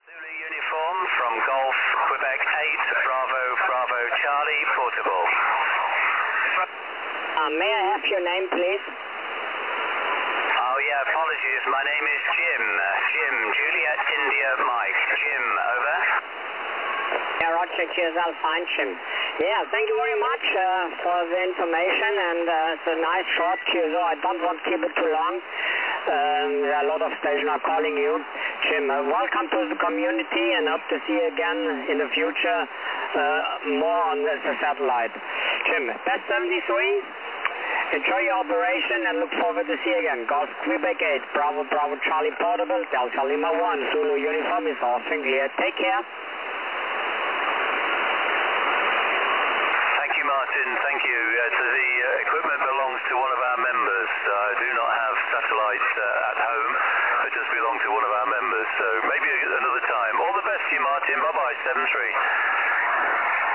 QSO`s via QO-100